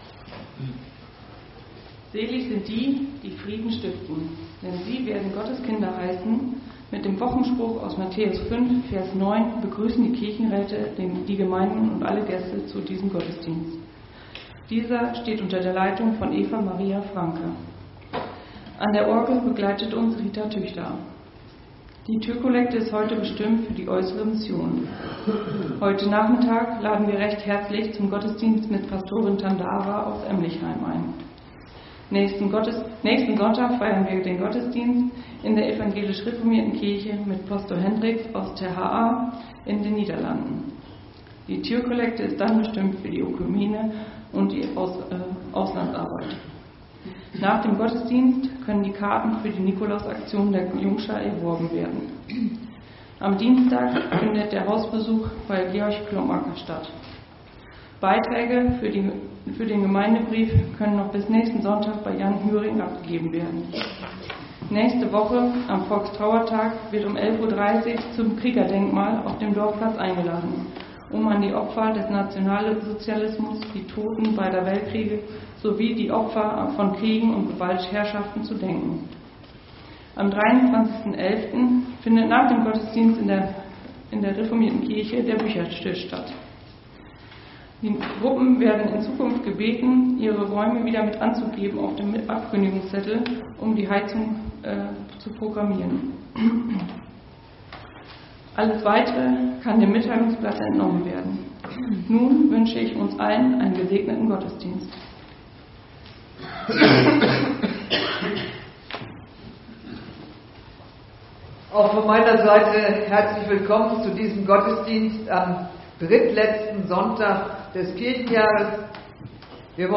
Gottesdienst Sonntag 09.11.2025 | Evangelisch-altreformierte Kirchengemeinde Laar
Wir laden ein, folgende Lieder aus dem Evangelischen Gesangbuch mitzusingen: Lied 177, 2, Lied 161, 1 – 3, Lied 152, 1 – 4, Lied 426, 1 – 3, Lied 391, 1 – 4, Lied 316, 1 – 4
Andacht Sonntag 09.11.2025 als Audiodatei zum Download